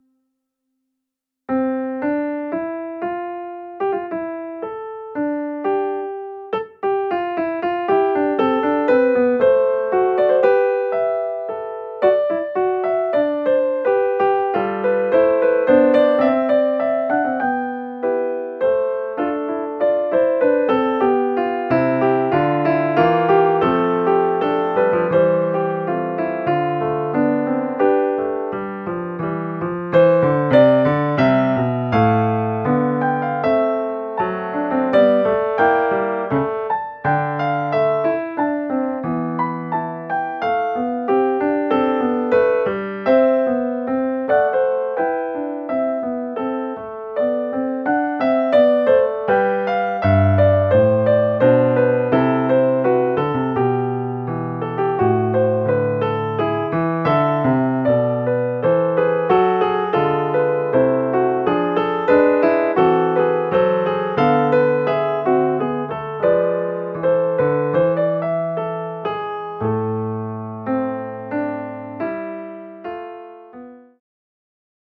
PianoSolo2.mp3